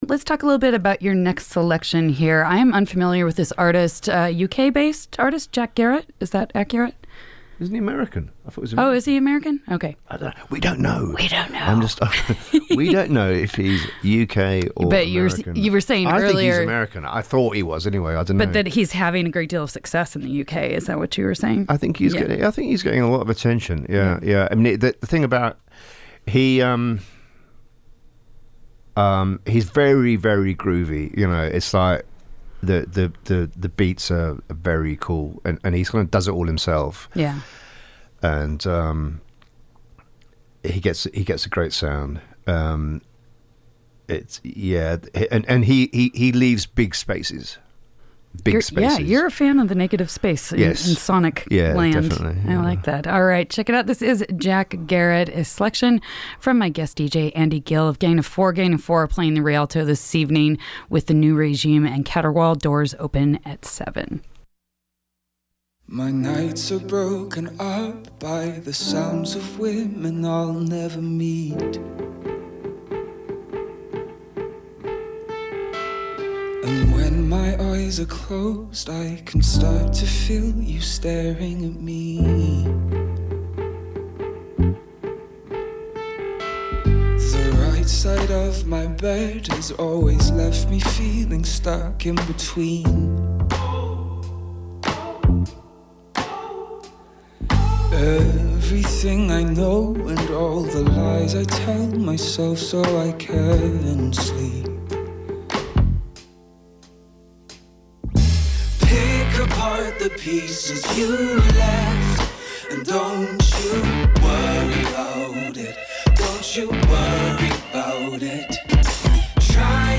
Listen to Part Two of Our Guest DJ Set with Gang of Four Leader Andy Gill